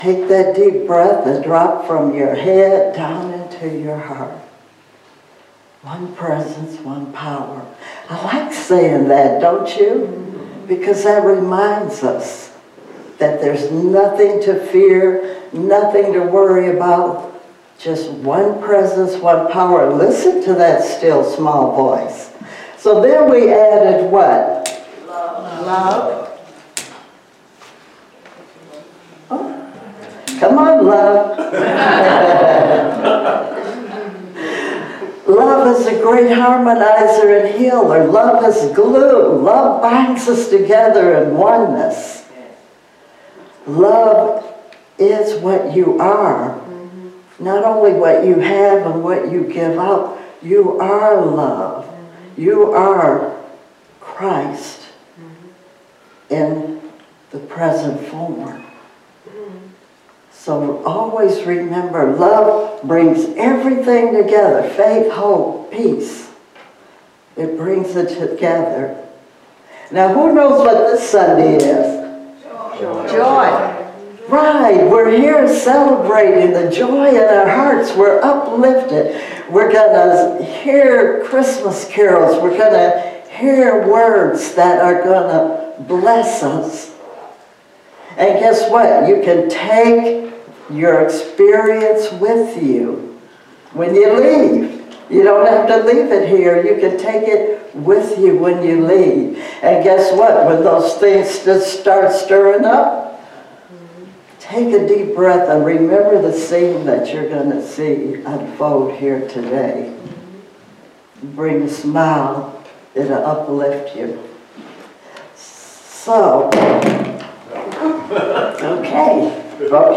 12/21/25 ~ Symbols of the Season: Christmas Candle Lighting Service
25-Christmas-Candle-lighting-service.mp3